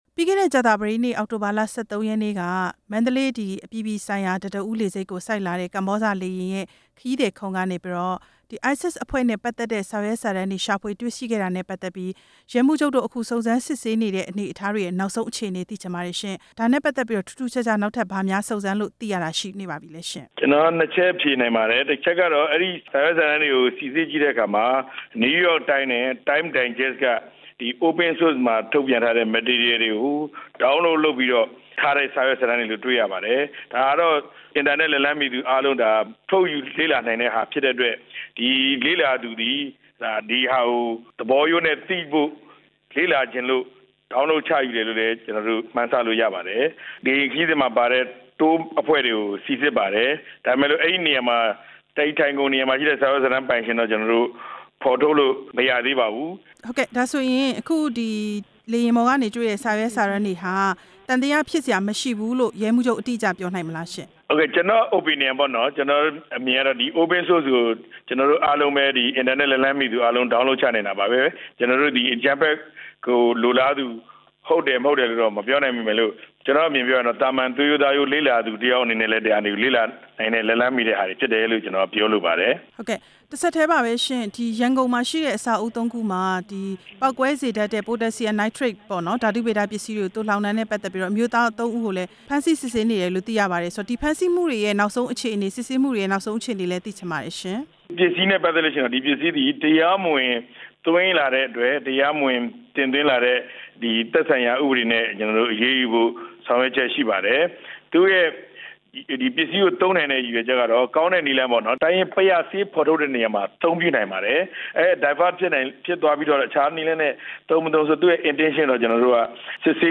အကြမ်းဖက်မှုအန္တရာယ် ကာကွယ်နိုင်ရေး မေးမြန်းချက်